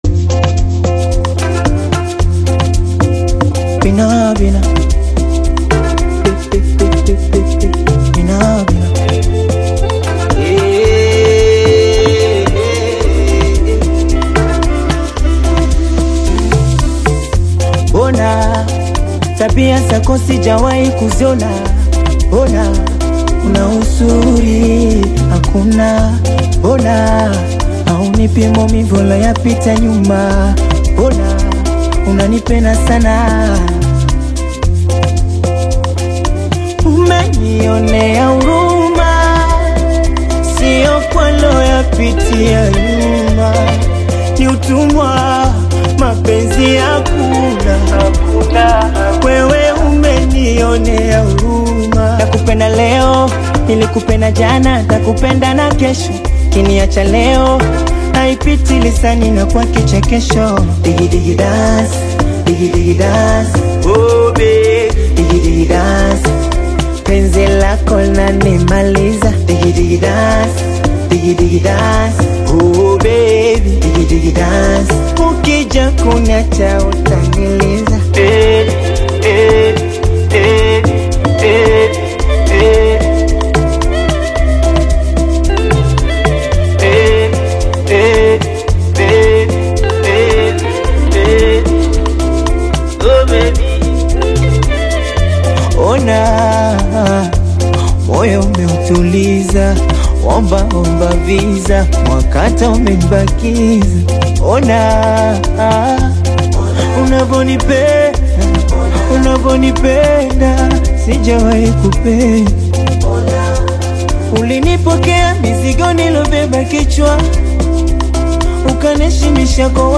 soulful Afro-Pop/Bongo Flava track